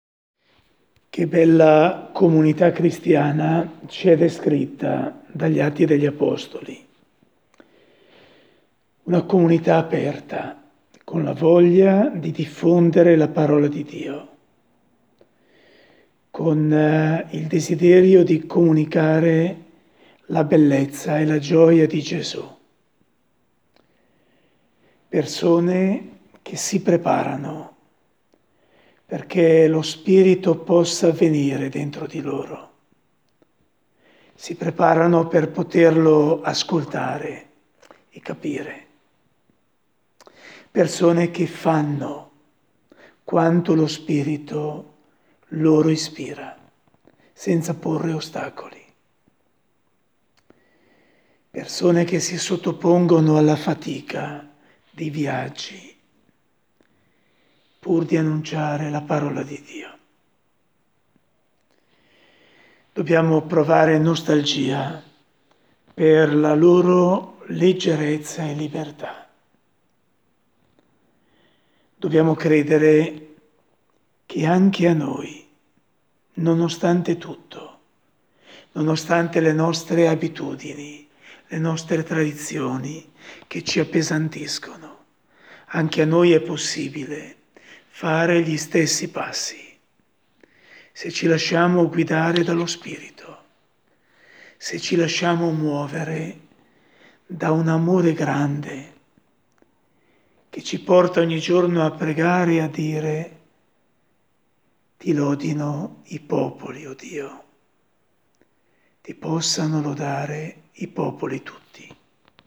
Qui il commento alle letture di oggi